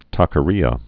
(täkə-rēə)